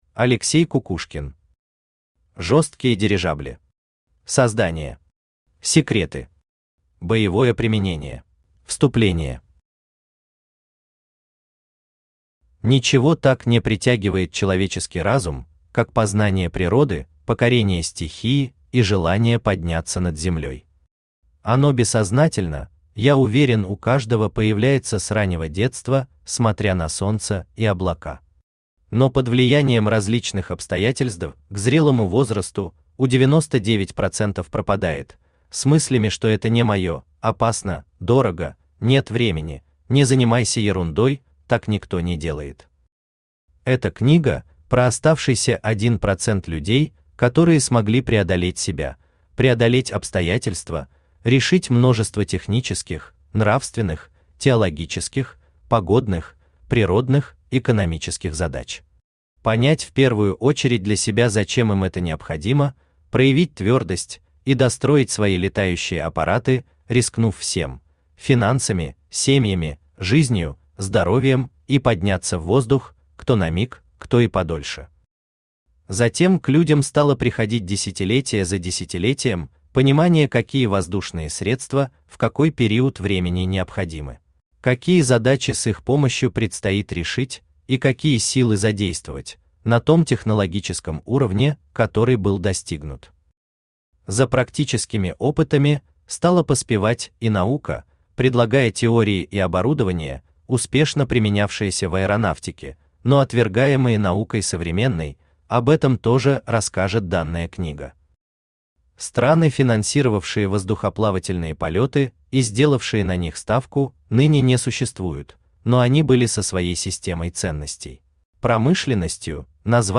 Аудиокнига Жесткие дирижабли. Создание. Секреты. Боевое применение | Библиотека аудиокниг
Боевое применение Автор Алексей Николаевич Кукушкин Читает аудиокнигу Авточтец ЛитРес.